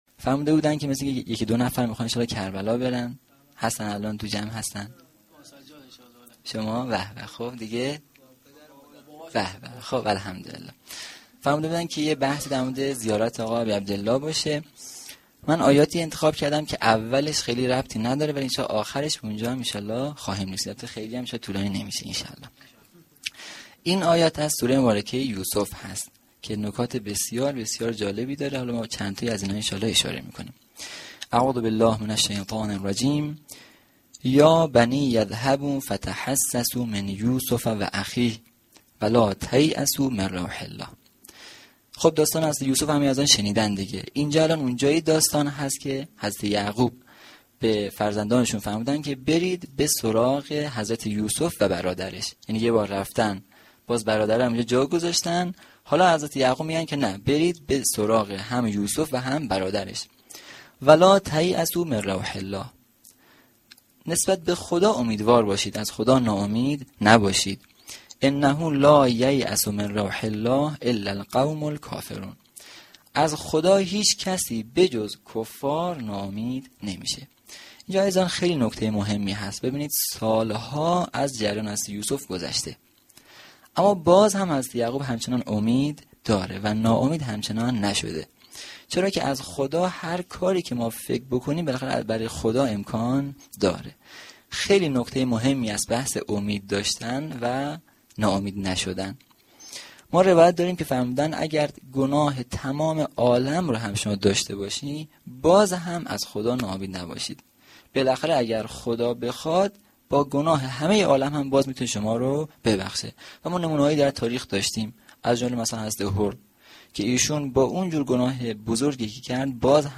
روضه العباس
sokhanrani.mp3